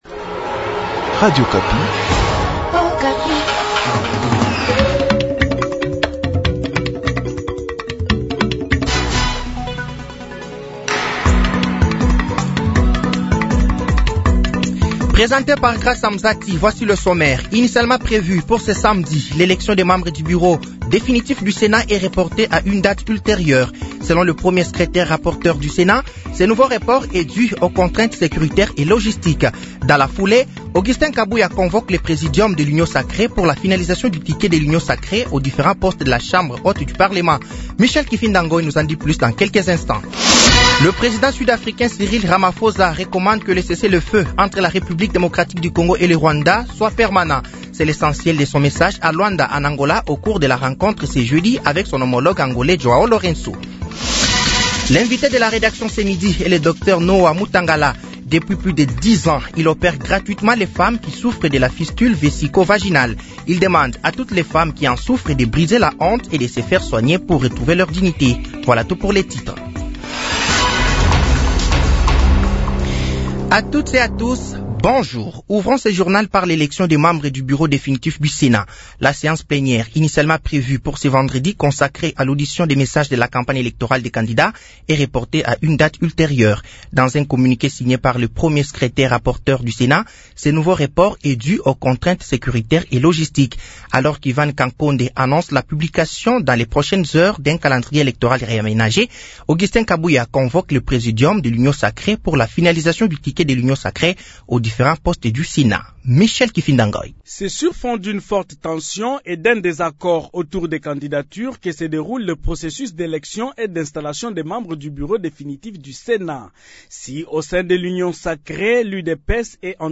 Journal français de 12h de ce vendredi 09 août 2024